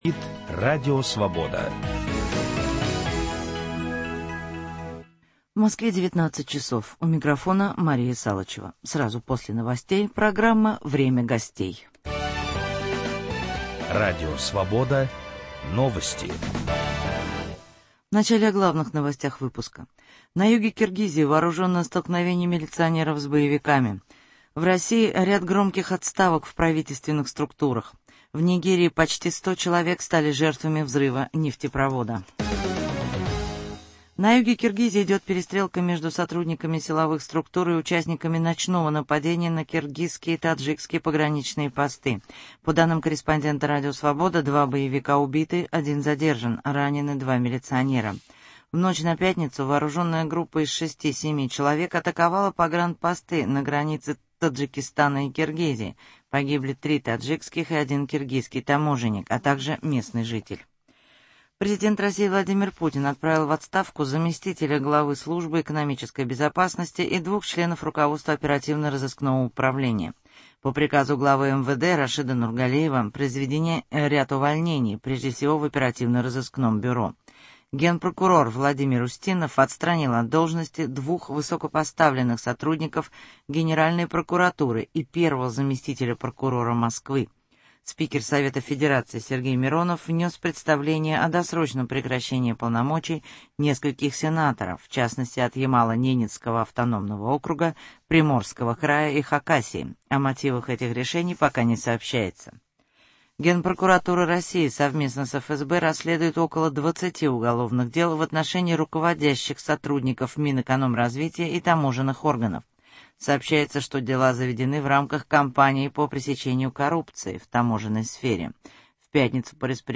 В программе, посвященной 30-летнему юбилею Московской Хельсинкской группы, выступит один из основателей диссидентского движения в России Владимир Буковский.